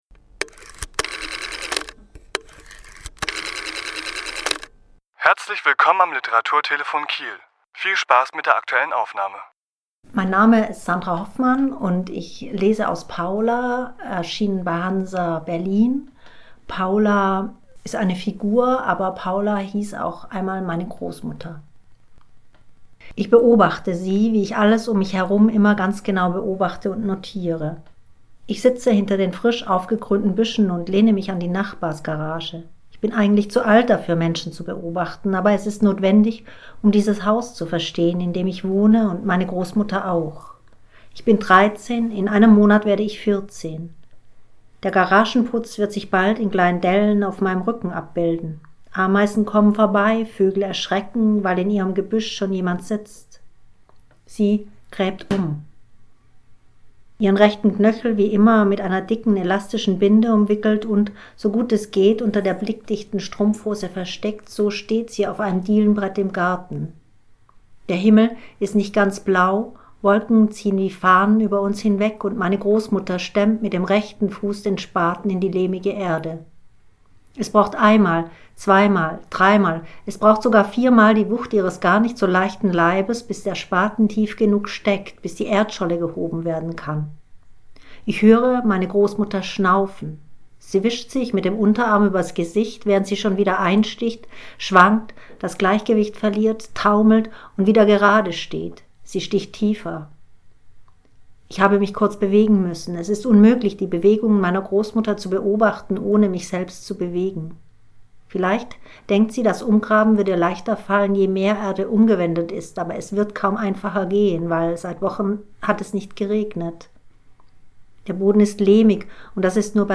Autor*innen lesen aus ihren Werken
Die Aufnahme entstand im Rahmen einer Lesung im Literaturhaus Schleswig-Holstein am 23.1.2018.